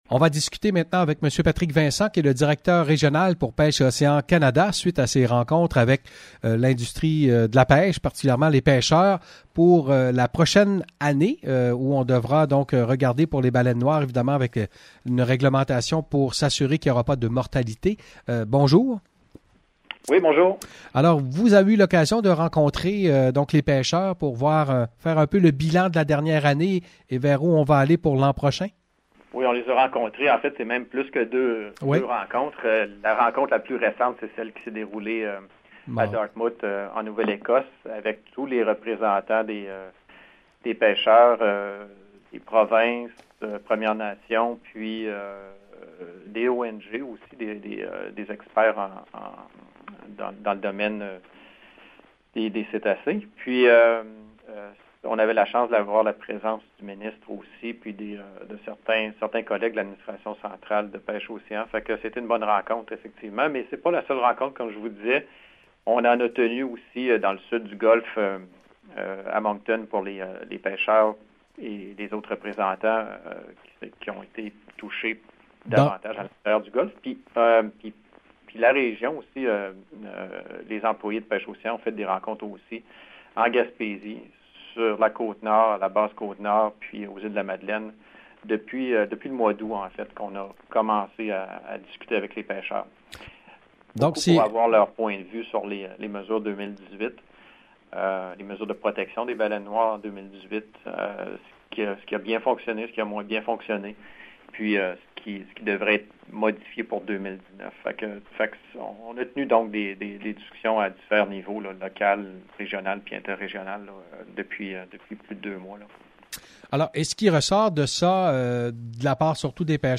Lundi à l’émission O’Leary vous informe, nous avons parlé des mesures de protection pour les baleines noires. Les pêcheurs affirment qu’il doit y avoir des modifications de la part de Pêches et Océans Canada.